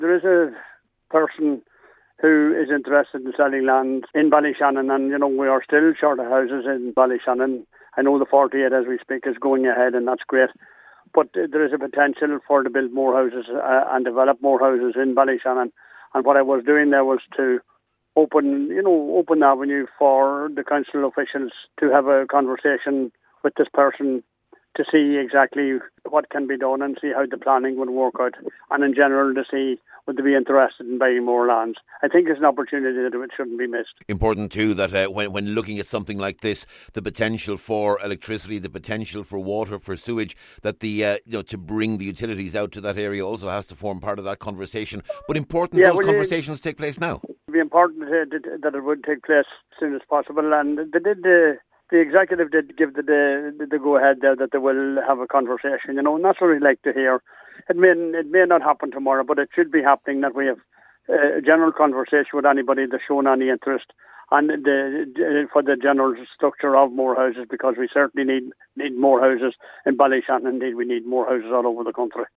Cllr McMahon says it’s vital that this is followed up…………….